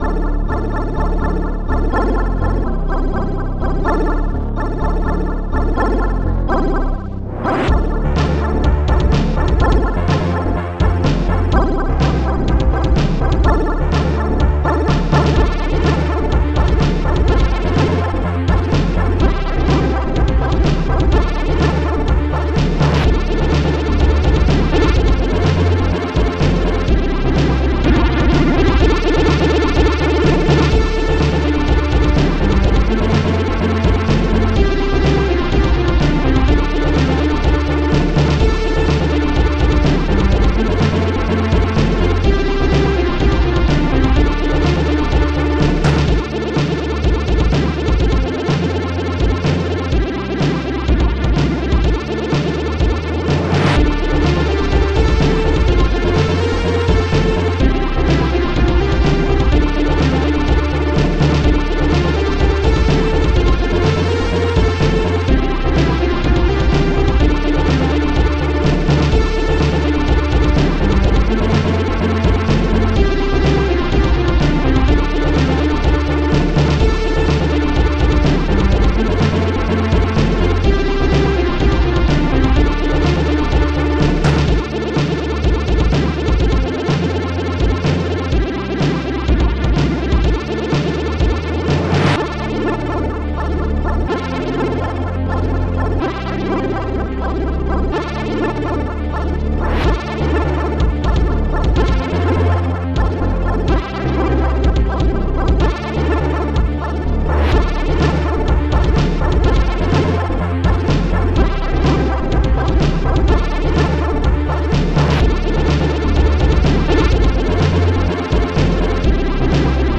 Protracker and family
st-01:AnalogString
st-02:MonsterBass
st-03:darksnare
st-03:BassDrum5
st-03:Claps1
st-03:Shaker